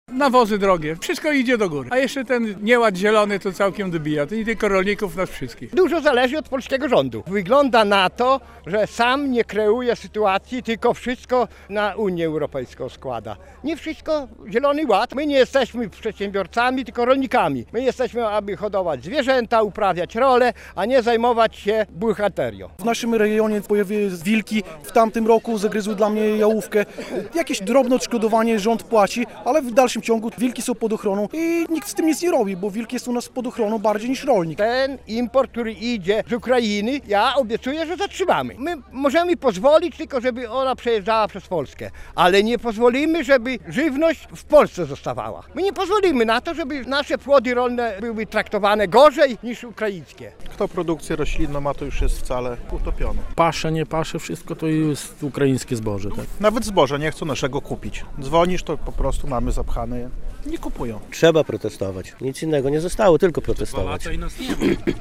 Ponad 200 rolników protestowało w dwóch miejscach w Knyszynie - relacja